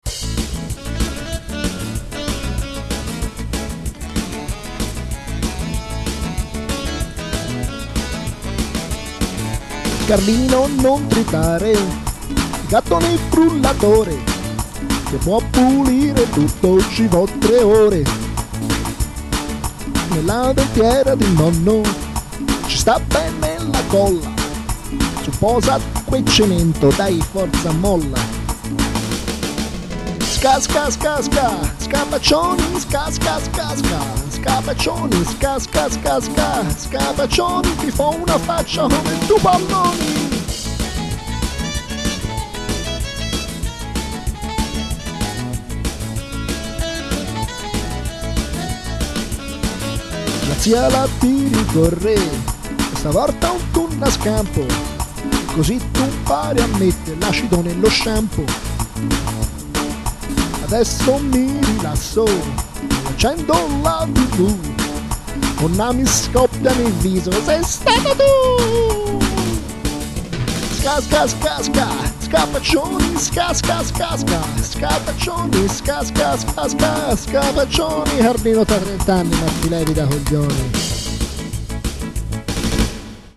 Era da tanto che volevo fare un pezzo ska... in attesa di farlo col gruppo ecco una versione sequencerizzata